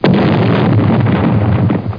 00137_Sound_explood.mp3